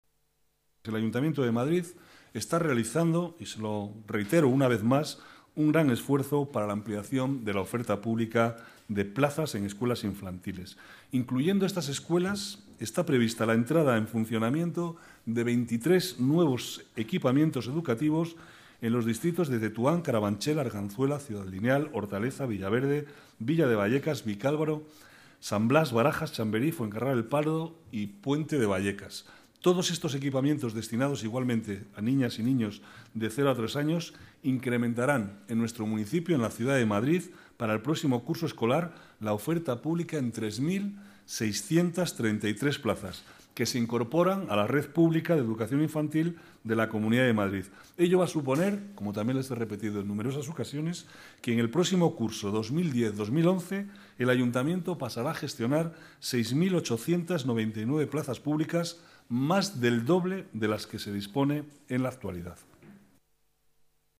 Nueva ventana:Declaraciones del vicealcalde, Manuel Cobo, sobre las escuelas infantiles de Latina